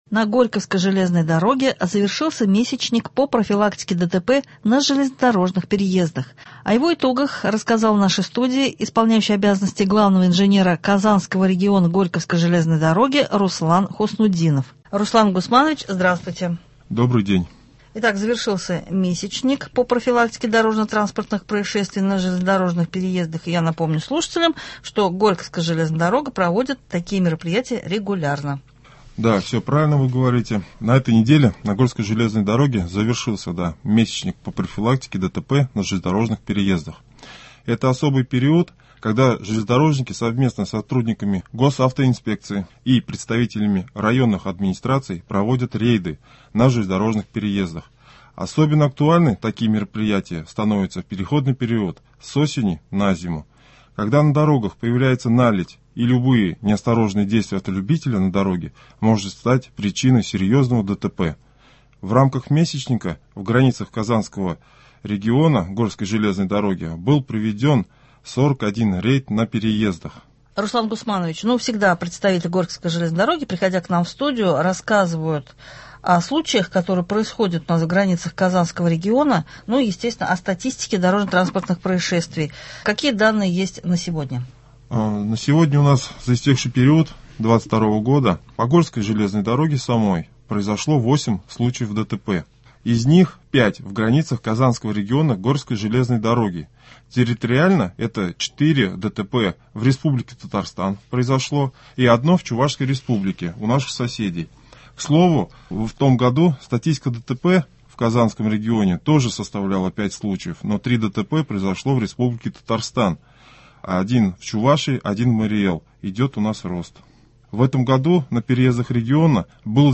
На Горьковской железной дороге завершился месячник по профилактике ДТП на железнодорожных переездах. О его итогах рассказал в нашей студии